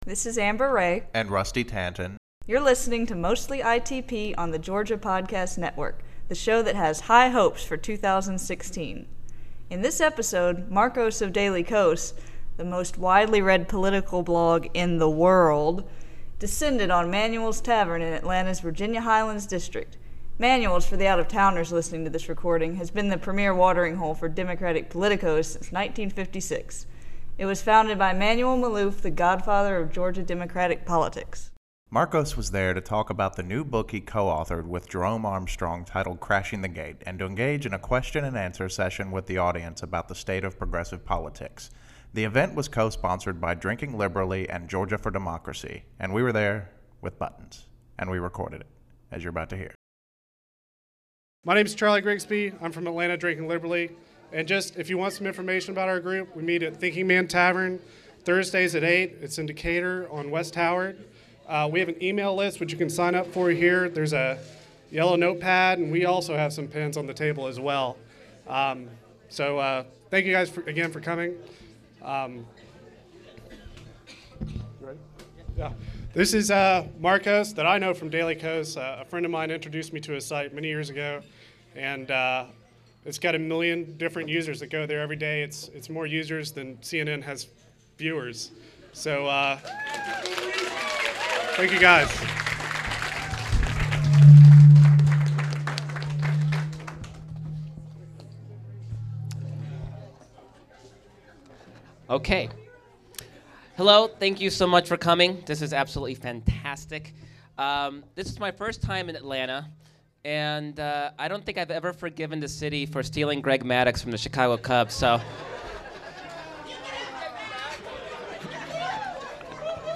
Event - Crashing the Gate book signing | Georgia Podcast Network
Show notes In this episode, Markos Moulitsas Zúniga of Daily Kos — the most widely read political blog in the world — descended on Manuel's Tavern in Atlanta's Virginia Highland district.
Markos was there to talk about the new book he co-authored with Jerome Armstrong titled Crashing the Gate , and to engage in a question and answer session with the audience about the state of progressive politics.